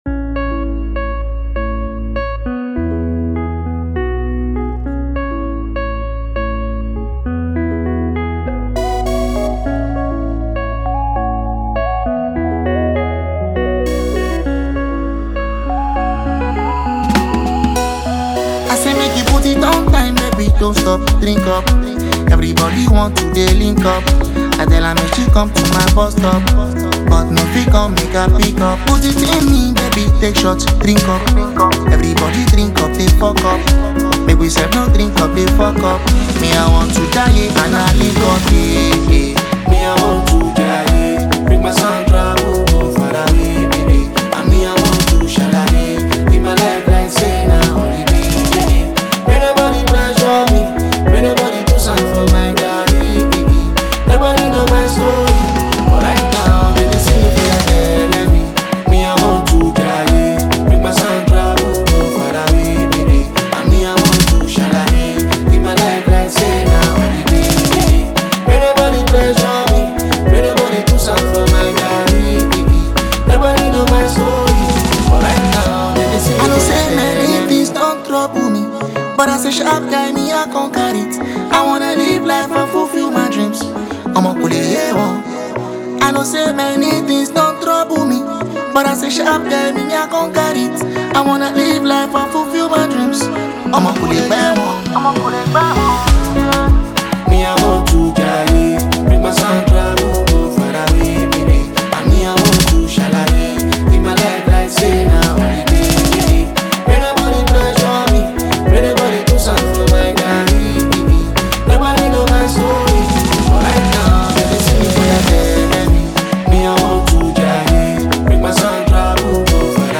is a motivational anthem that talks about enjoying life